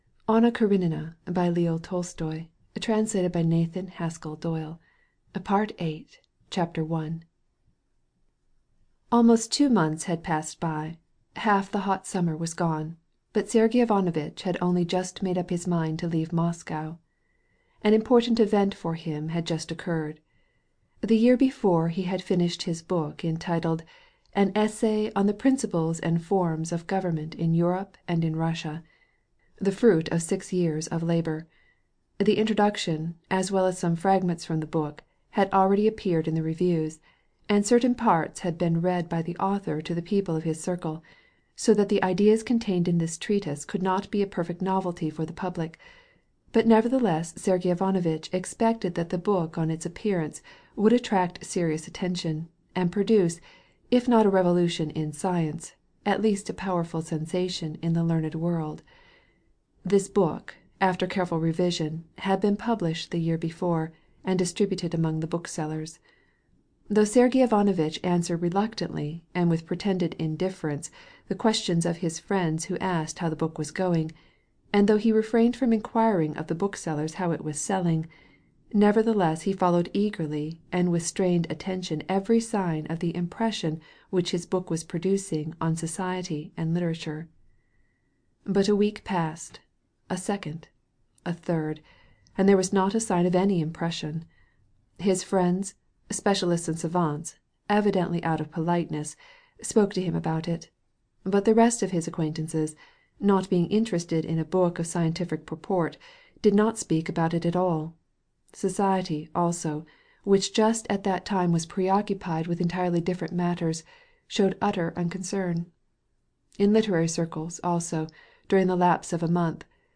Anna Karenina by Leo Tolstoy (Section 4) ~ Full Audiobook [romance]